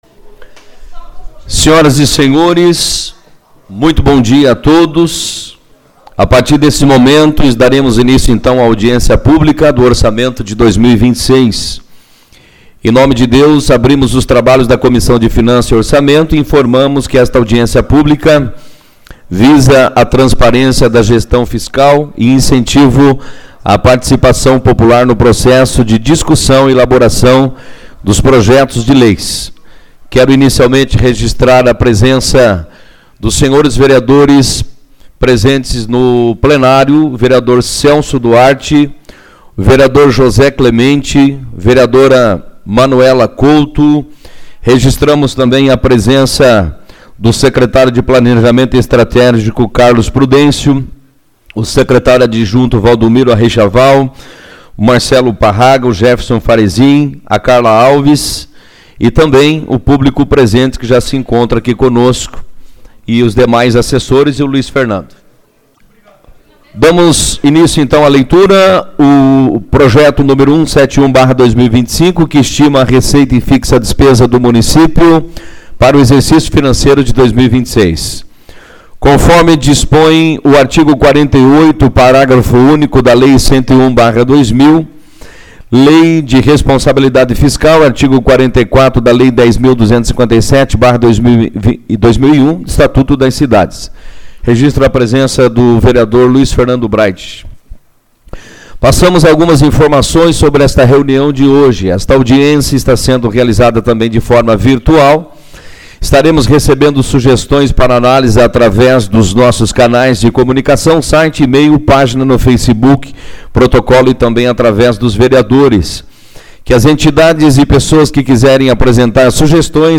Audiência Pública-LOA 2026